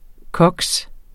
Udtale [ ˈkʌgs ]